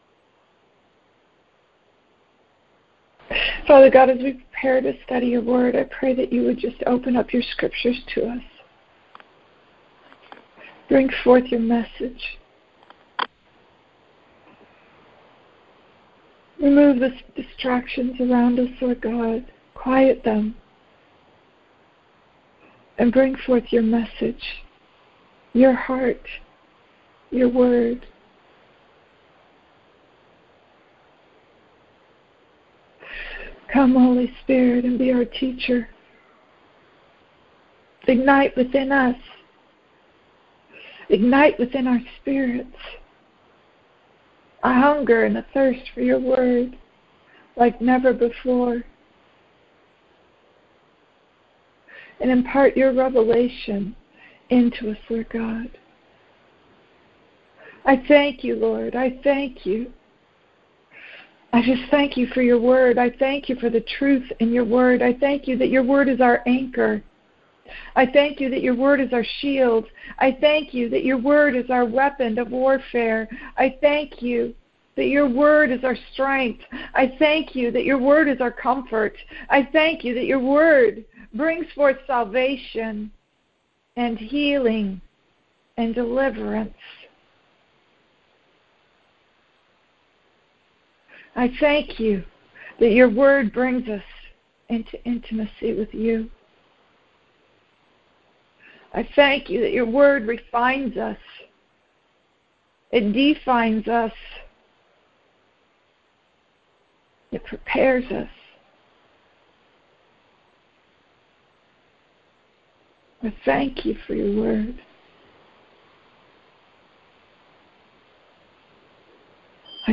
These are some of the challenges made in this timely teaching on 1 Thessalonians.